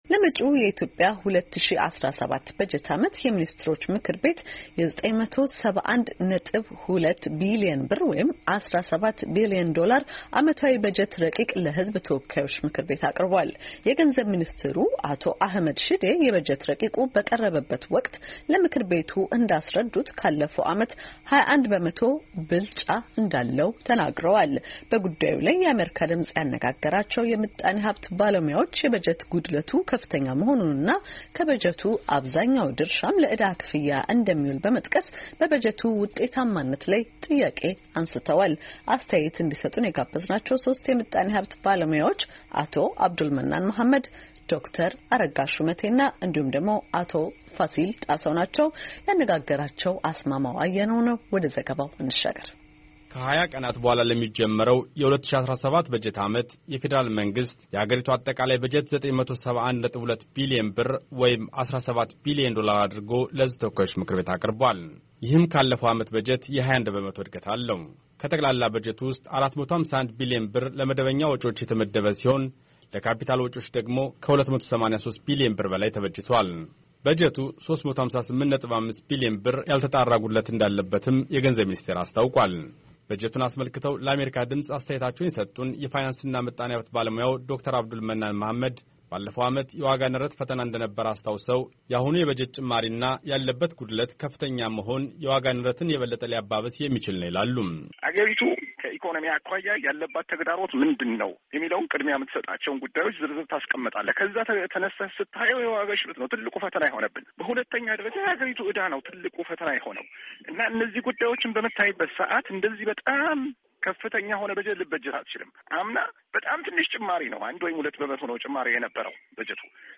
በጉዳዩ ላይ የአሜሪካ ድምፅ ያነጋገራቸው የምጣኔ ሀብት ባለሞያዎች፣ የበጀት ጉድለቱ ከፍተኛ መኾኑንና ከበጀቱ ብዙ ድርሻ ለዕዳ ክፍያ እንደሚውል በመጥቀስ፣ በበጀቱ ውጤታማነት ላይ ጥያቄ አንሥተዋል።